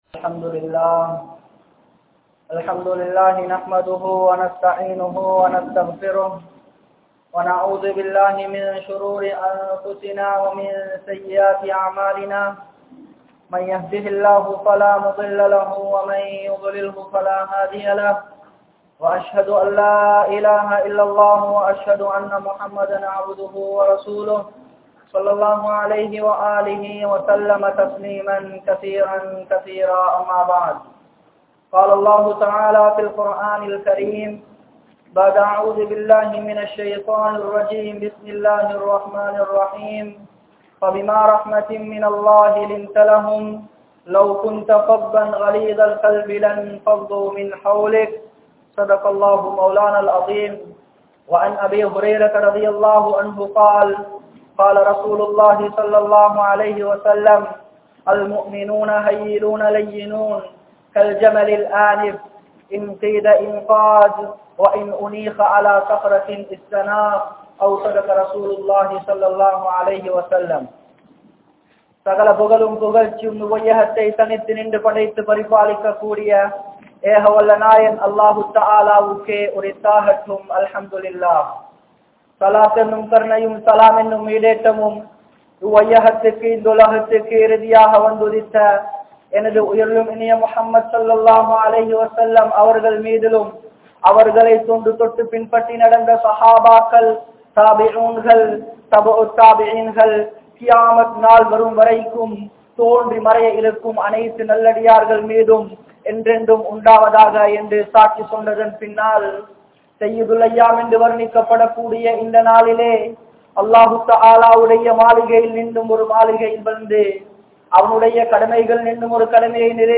Ahlaaq | Audio Bayans | All Ceylon Muslim Youth Community | Addalaichenai